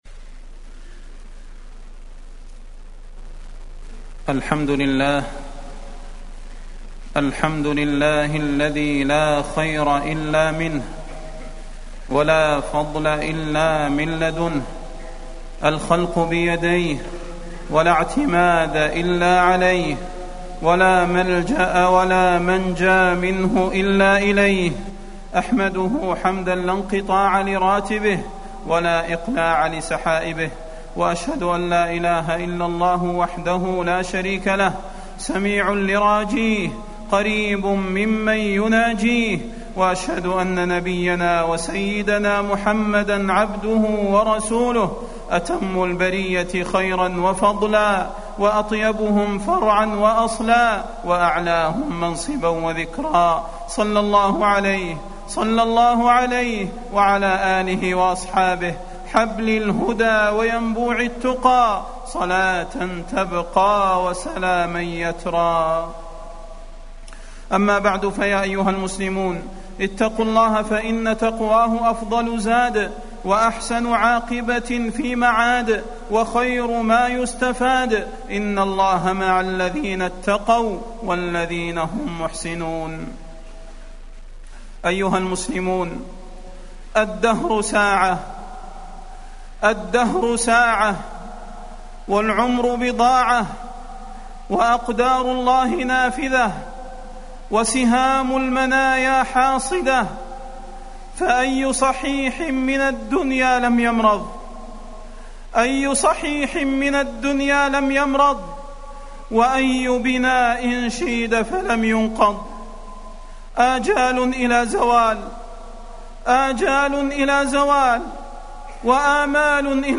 تاريخ النشر ٢ محرم ١٤٢٩ هـ المكان: المسجد النبوي الشيخ: فضيلة الشيخ د. صلاح بن محمد البدير فضيلة الشيخ د. صلاح بن محمد البدير الموت والتوبة